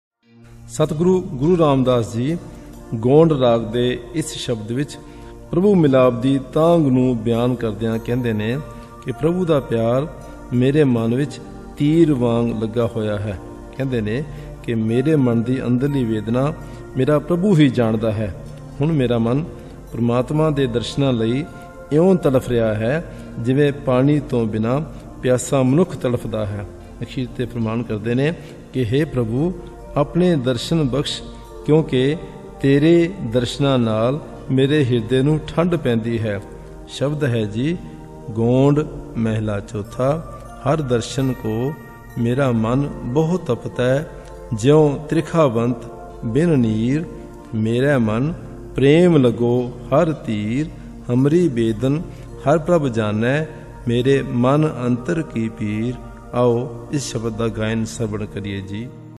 Commentary on this Shabad